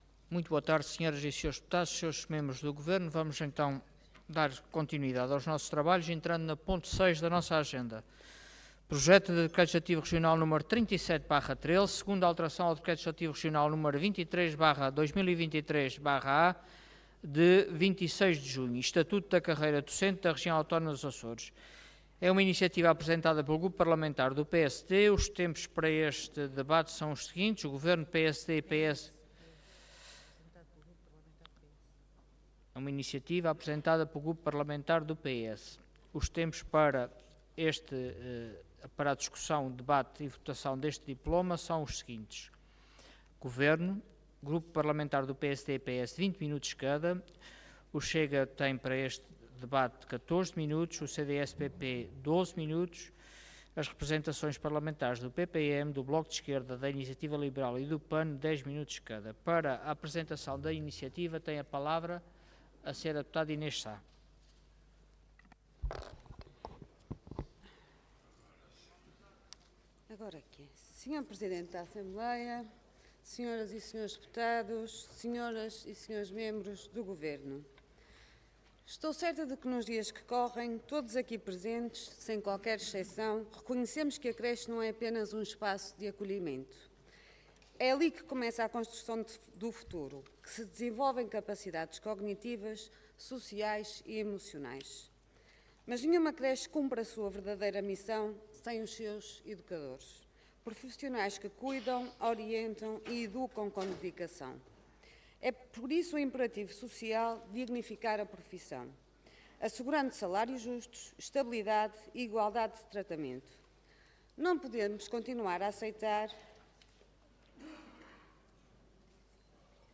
Intervenção
Orador Inês Sá Cargo Deputada Entidade PS